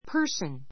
person 中 A1 pə́ː r sn パ ～ ス ン 名詞 ❶ 人 ⦣ 年齢 ねんれい ・性別に関係なく使う.